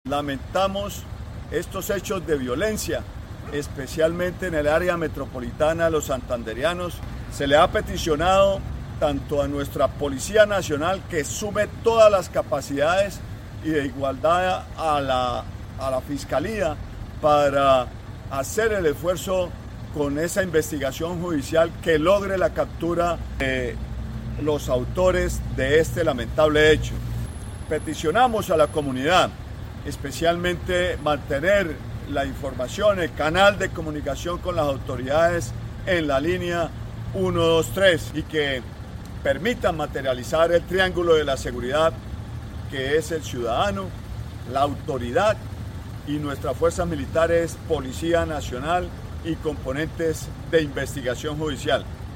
Oscar Hernández, Secretario del Interior de Santander